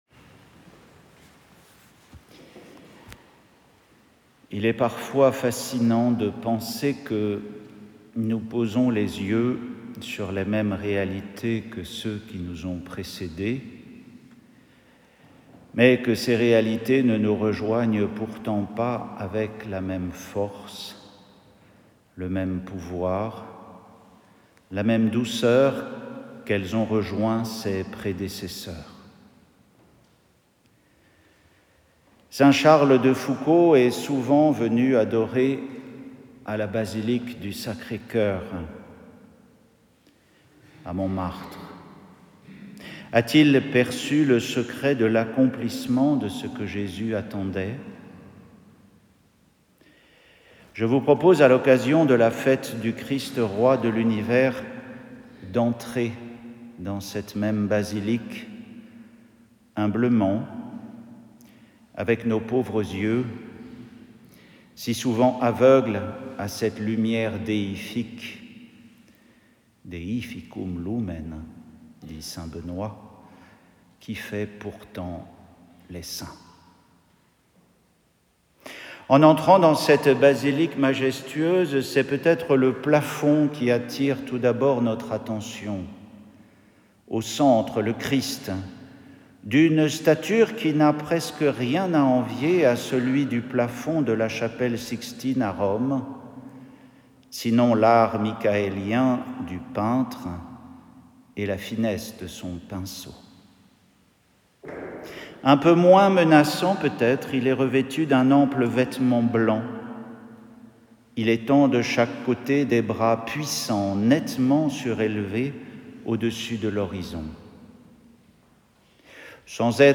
Homélie pour le dimanche du Christ Roi de l’Univers
Abbaye Notre-Dame des Neiges, 23 novembre 2025